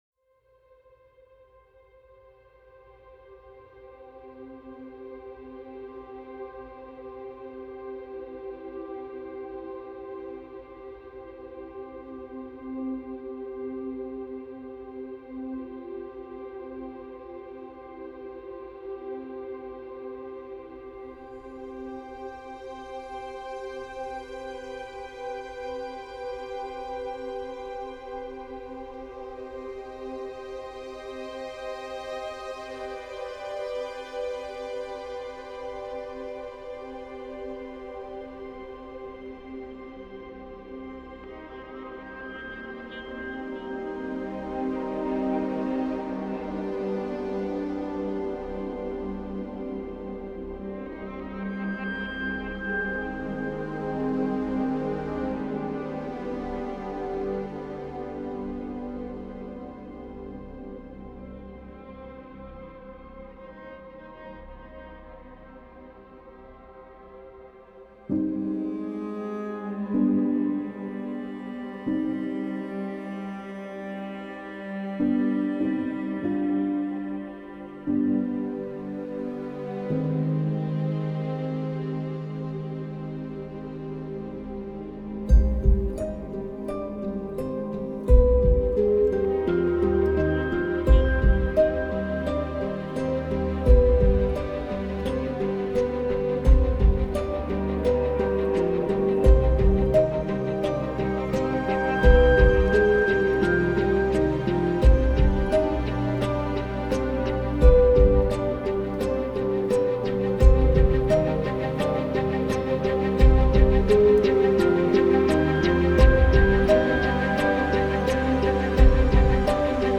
Genre : FilmsGames, Film Scores